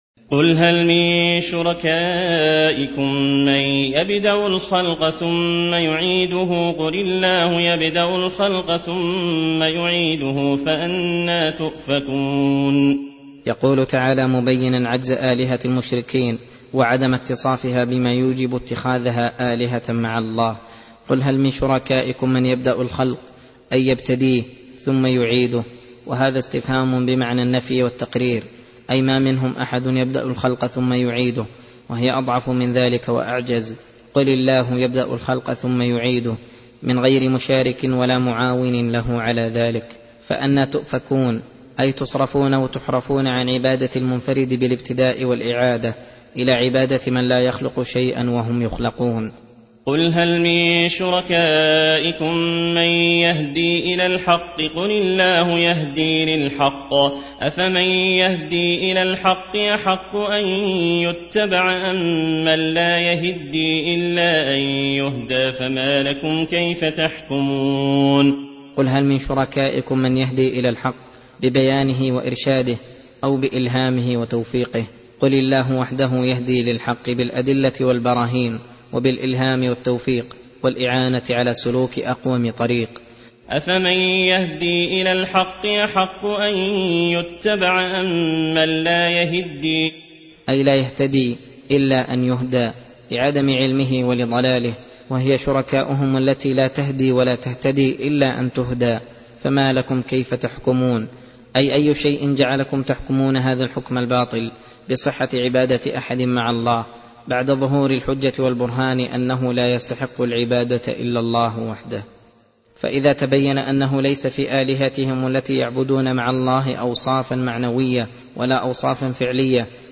درس (27) : تفسير سورة يونس : (34- 58)